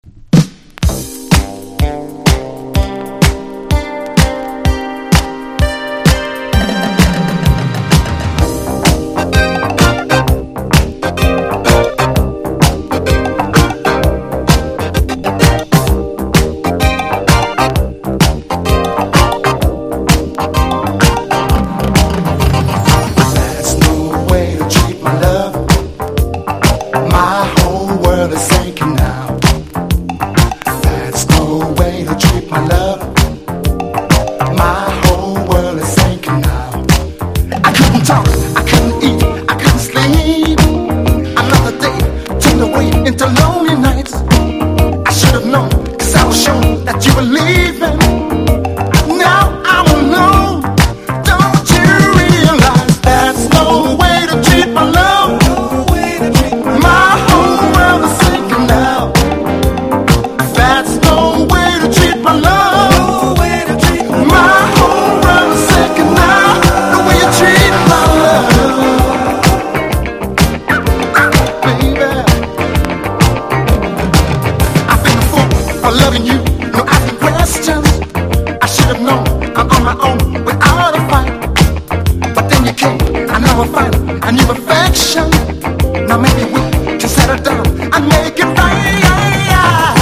サビにかけてのヴォーカルの盛り上がりと間奏のトロピカルなギターが最高です！